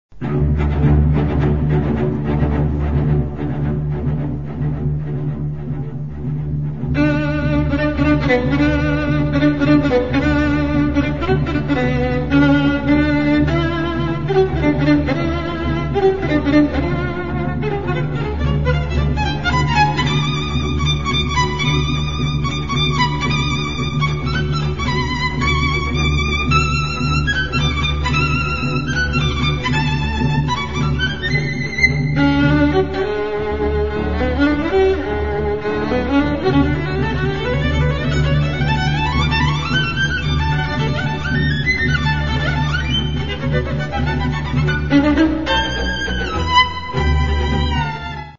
Un sens inné du phrasé, une technique à faire pâlir les virtuoses eux-même.
Tout la panoplie des coups d'archets est présente dans ce concerto, ricochets, staccato, martelés etc...
le tempo, pas trop rapide donne à l'œuvre un caractère encore plus profond s'il en était besoin.
impressionnante de douceur dans les pianissimi
Pièces pour violon et orchestre